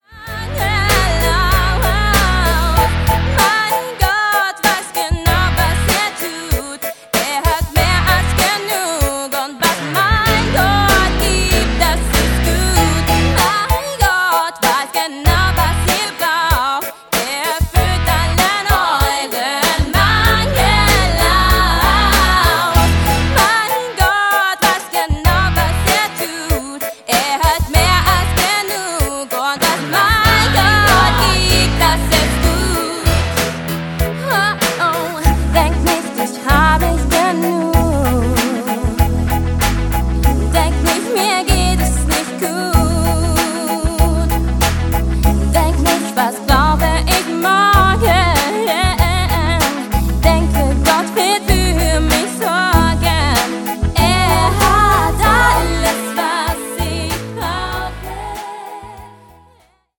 Einfach up-to-date-Grooves mit positiver Ausstrahlung!